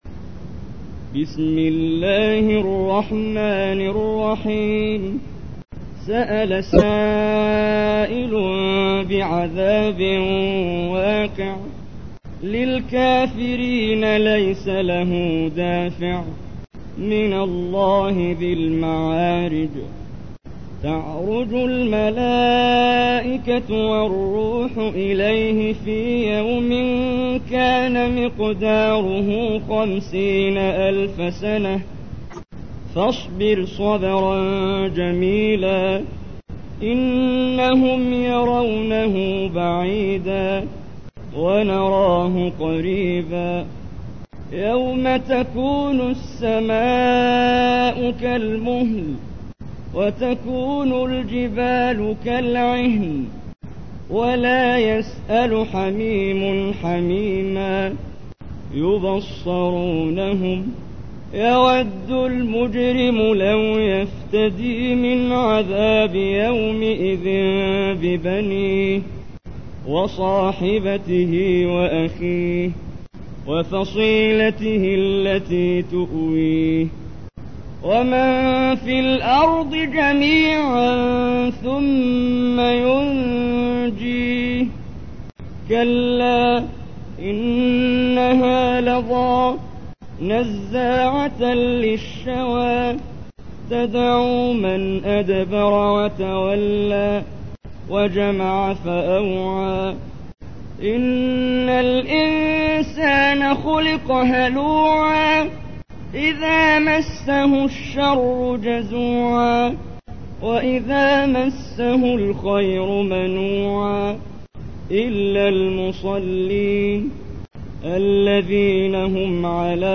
تحميل : 70. سورة المعارج / القارئ محمد جبريل / القرآن الكريم / موقع يا حسين